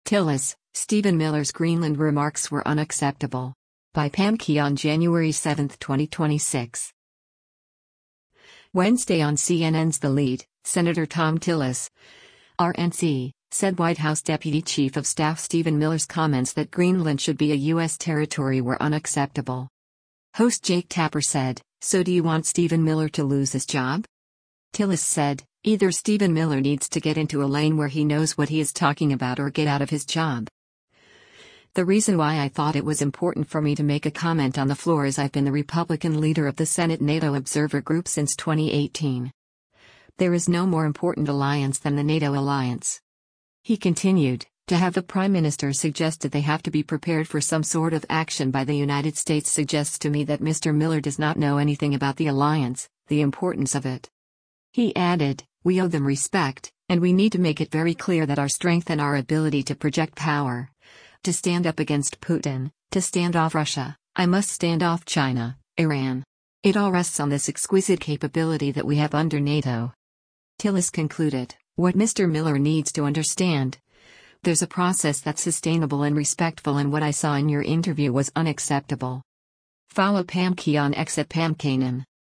Wednesday on CNN’s “The Lead,” Sen. Thom Tillis (R-NC) said White House deputy chief of staff Stephen Miller’s comments that Greenland should be a U.S. territory were “unacceptable.”
Host Jake Tapper said, “So do you want Stephen Miller to lose his job?’